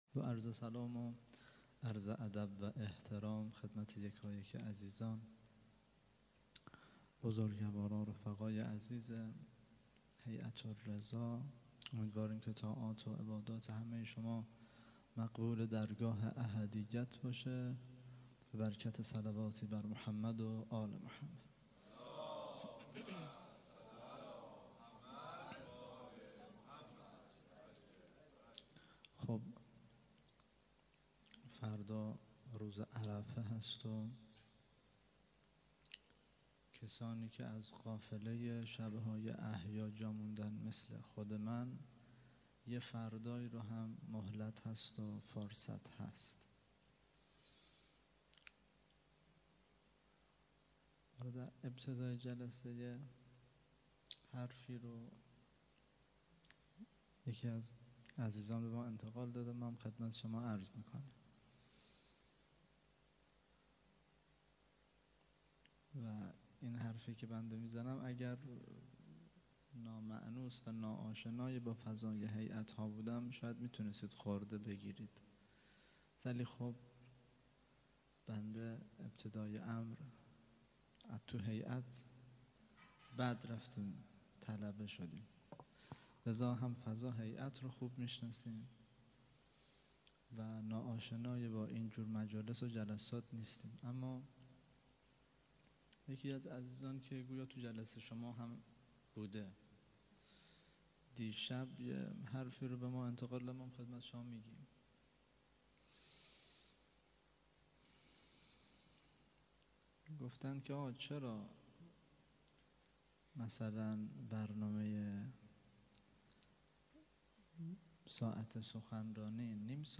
0- سخنرانی